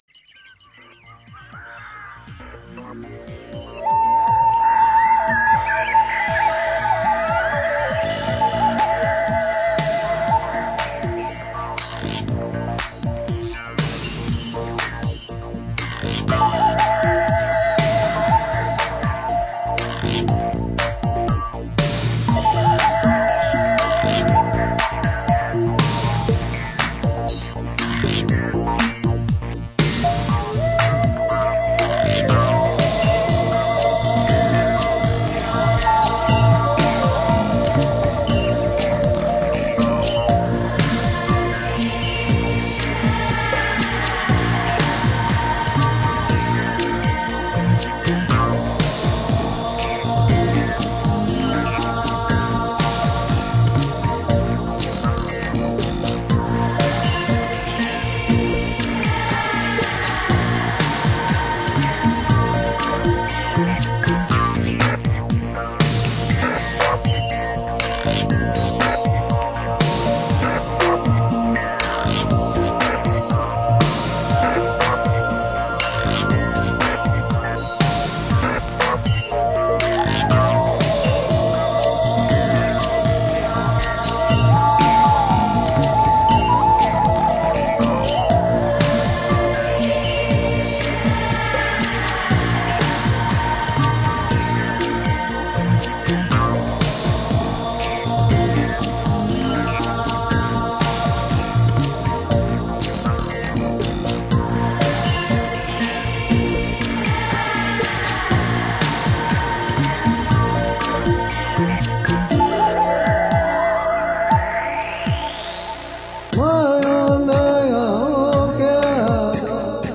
音乐类别 ： 新世纪音乐
专辑特色 ： 山川大地的脉动
为了使音乐更加通俗易懂，唱片的制作中加入了大量实地采样的真实效果，像真度之高堪称离奇，犹如置身深山老林，身临其境。
如果功放无力控制住疯狂的低频，喇叭分分钟拍边，弄得不好则烧而毁之。